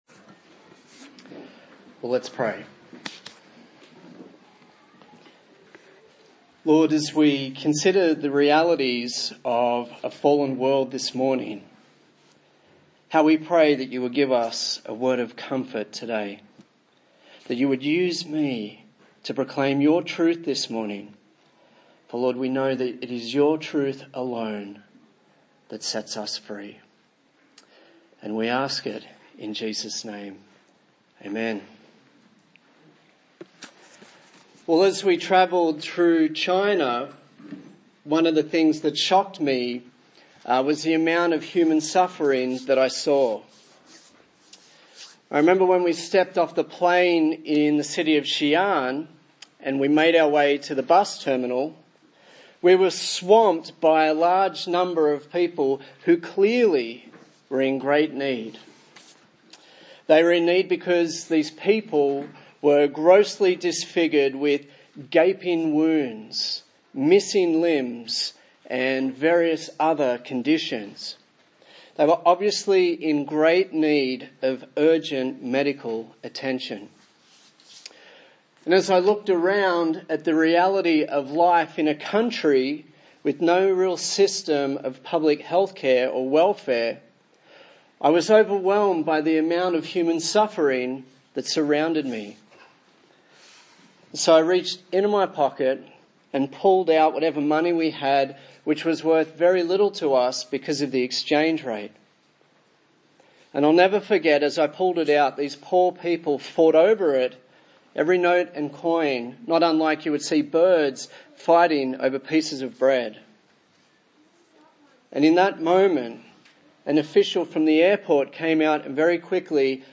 A sermon in the series on the book of John
Service Type: Sunday Morning